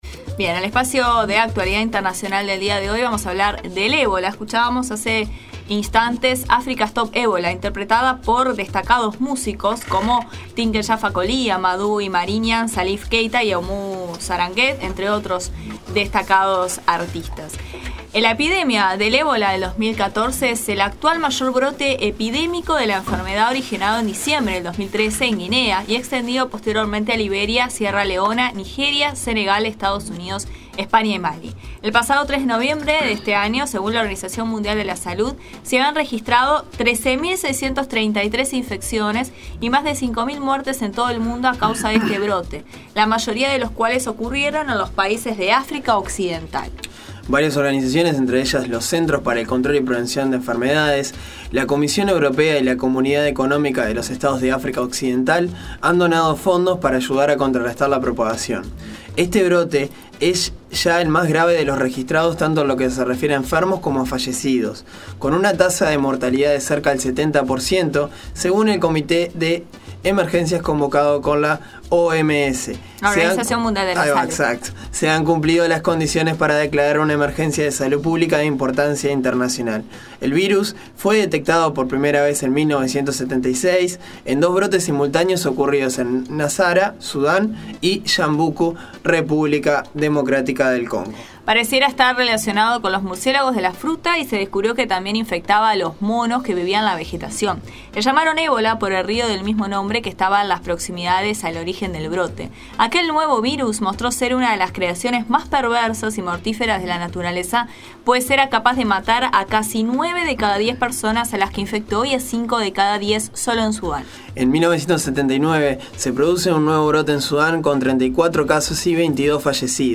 Audio: Epidemia del Ebola, entrevista